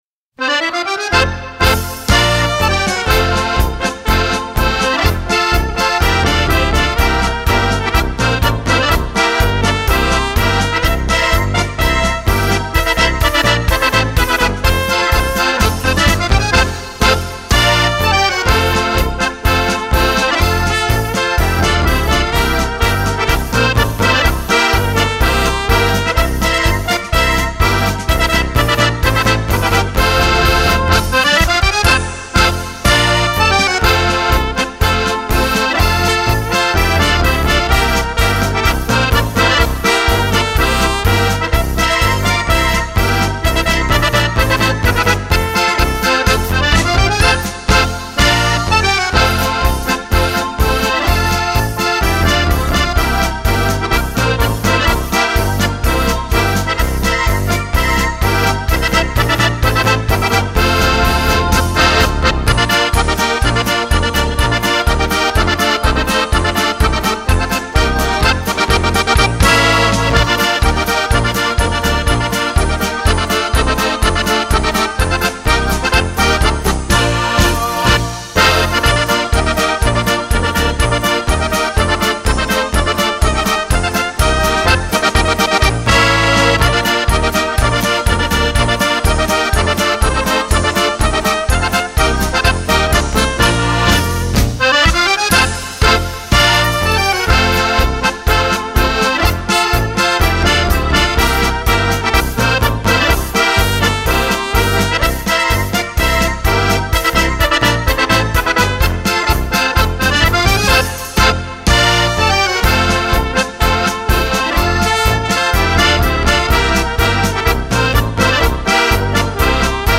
This Denver German Band is a versatile band that plays a wide variety of music including traditional German polkas and Schunkel waltzes as well as other beer drinking songs and of course the Chicken Dance.
Included in the Ballroom music is of course Tangos, Rhumbas, Mambos, Sambas, Cha Chas, Merengues and Cumbias and even a Tarantella or two.